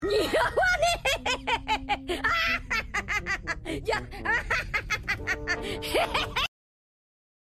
Luffy Laugh Sound Effect Free Download
Luffy Laugh